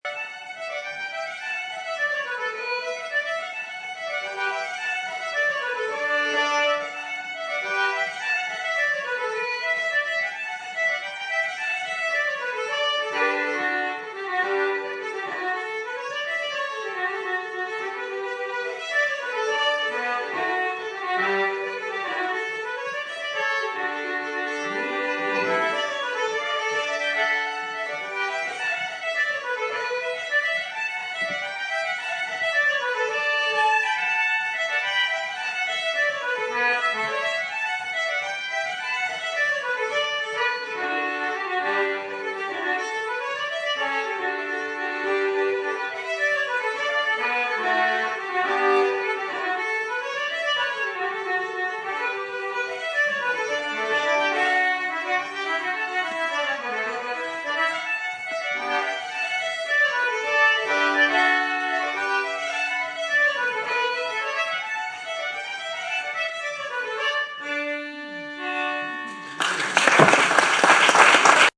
The strong surviving music traditions here have an unmistakeable character – fast and lively.
They are an excellent duo – here’s a little taster from that concert (Kerry Slides):
Saturday’s recital in Saint Matthew’s Church, Baltimore